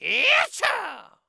binf_attack_2.wav